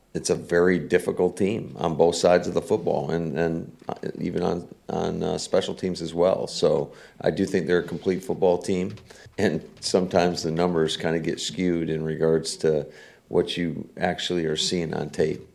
LaFleur talks Eagles: Packers coach Matt LaFleur shared his thoughts on this Monday’s opponent the Philadephia Eagles when he met with the media on Thursday.